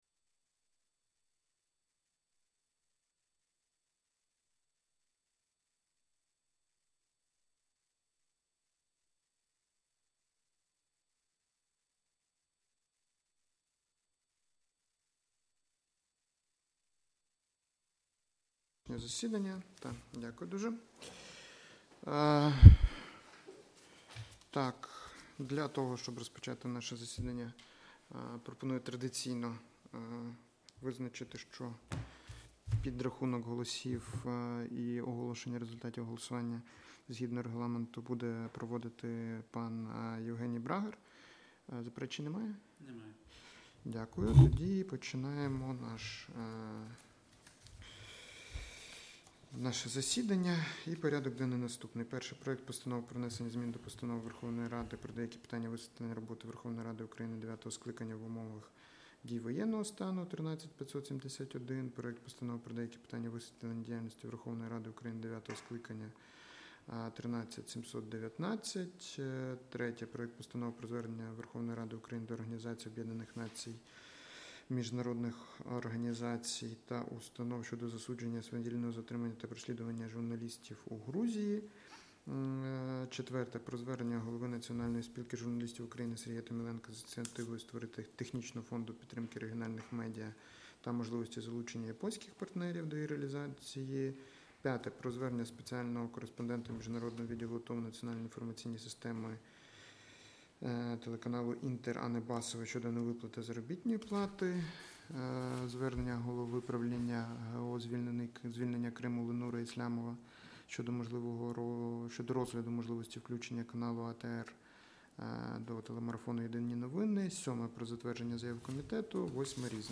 Аудіозапис засідання Комітету від 3 вересня 2025р.